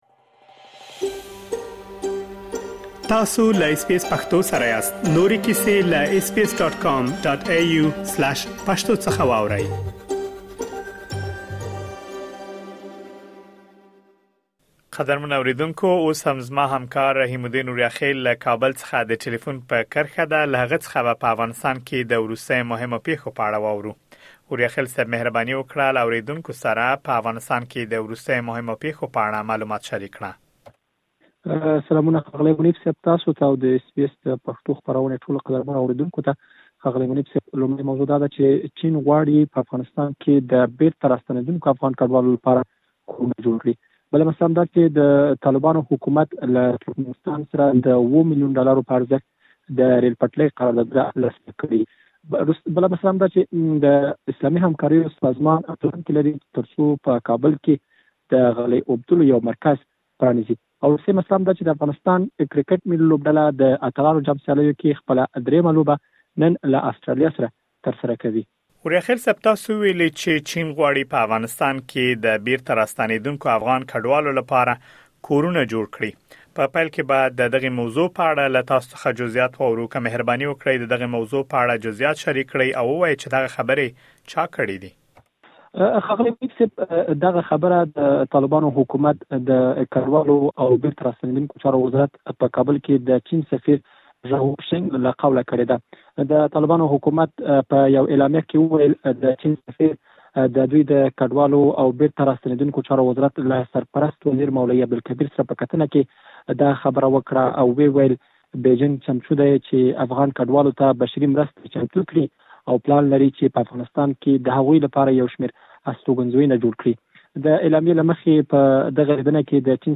د افغانستان د وروستیو پېښو په اړه مهم معلومات په ترسره شوې مرکې کې اورېدلی شئ.